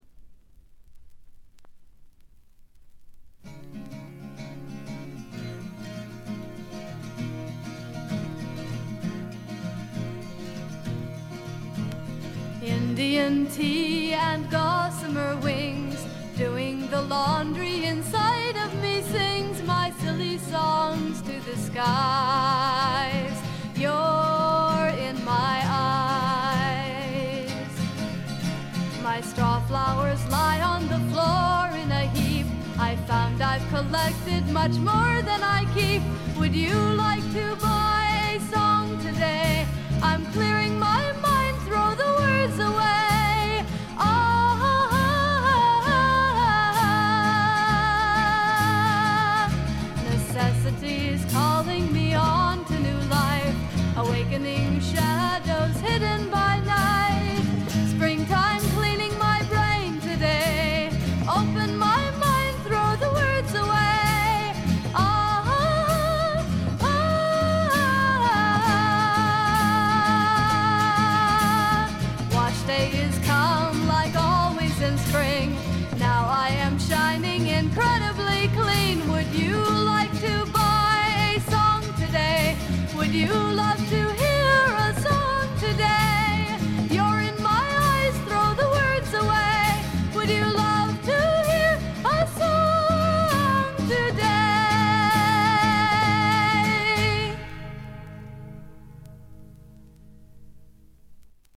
甘酸っぱい香りが胸キュンのまばゆいばかりの青春フォークの傑作。
Vocals, Guitar, Composed By ?